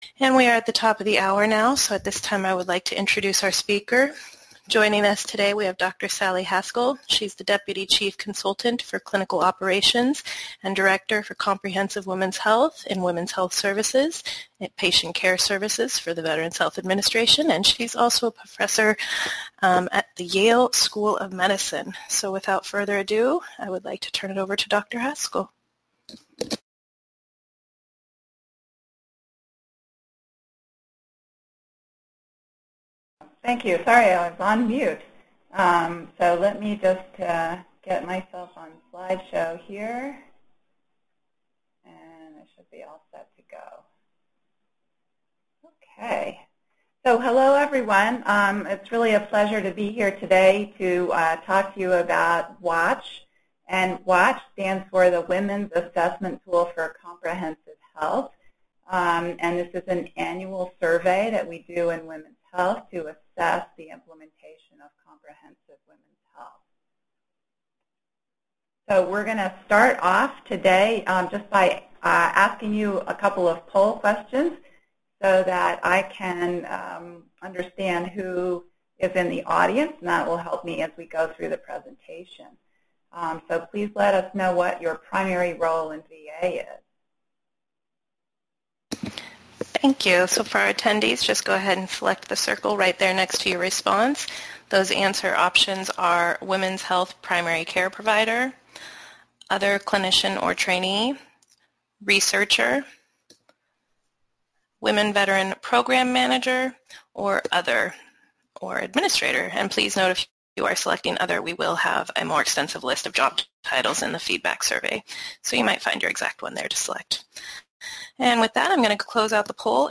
Description: This Cyber Seminar will use the data from the national Women’s Assessment Tool for Comprehensive Women’s Health (WATCH) to describe the successes and gaps in the implementation of Comprehensive Women’s Health across VHA.